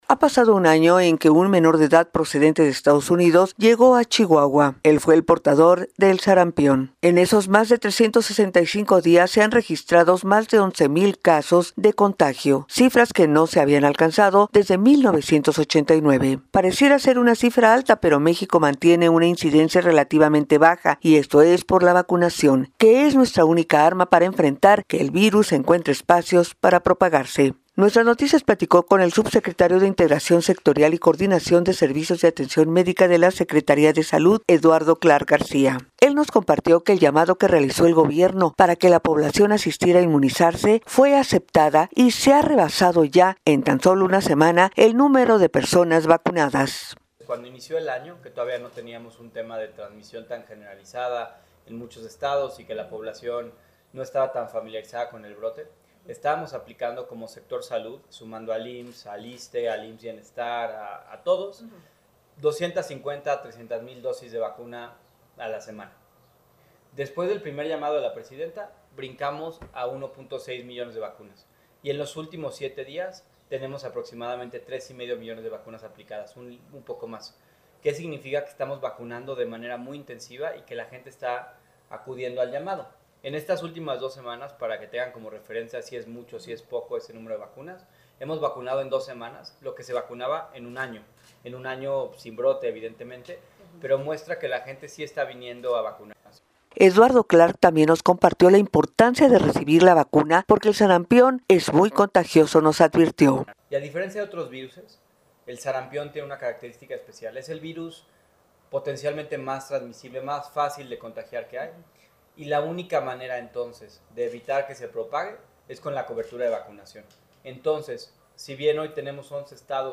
NUESTRAS NOTICIAS platicó con el subsecretario de Integración Sectorial y Coordinación de Servicios de Atención Médica de la secretaria de Salud, Eduardo Clark García. Quien llamo a la población en todo el país a sumarse a la aplicación de la vacuna contra, lo que él calificó, una enfermedad sumamente contagiosa.
DESDE SUS OFICINAS AL SUR DE LA CIUDAD DE MEXICO, EL SUBSECRETARIO RECORDO EL SECTOR DE LA POBLACIÓN QUE DEBE RECIBIR PRIORITARIAMENTE LA VACUNACION….